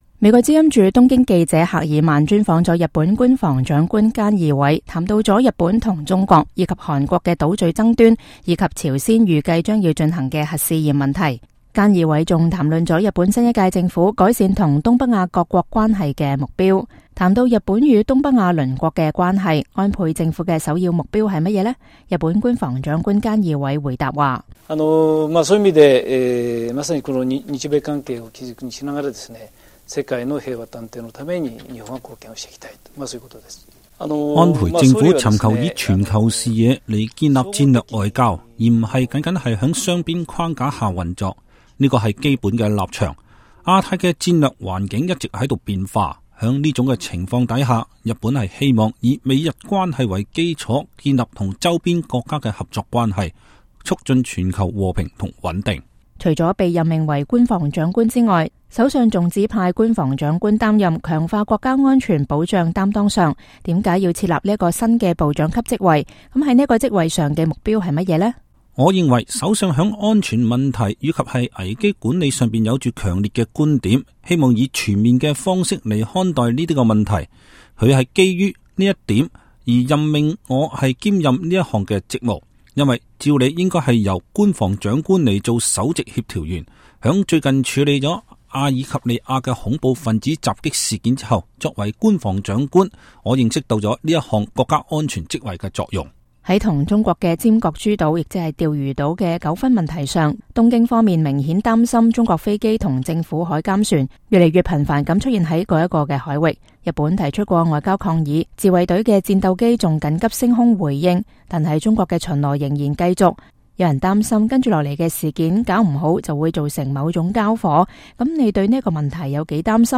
VOA專訪日本官房長官